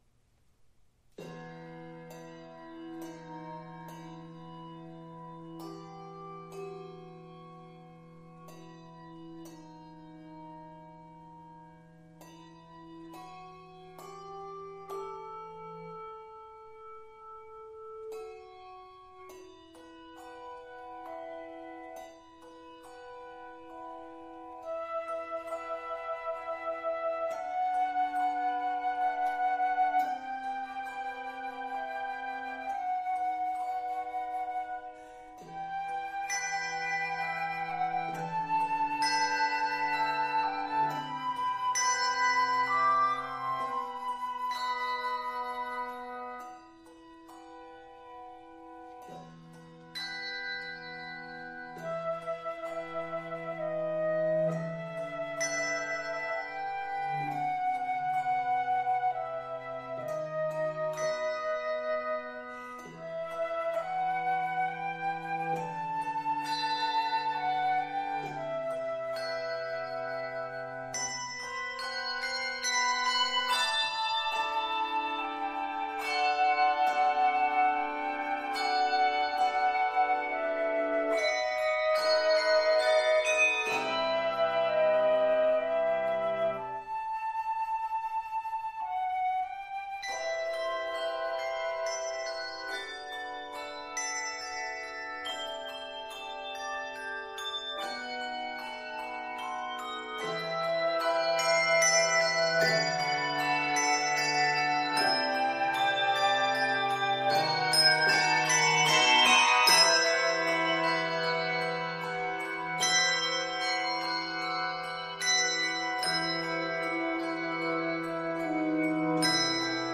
Key of e minor. 90 measures.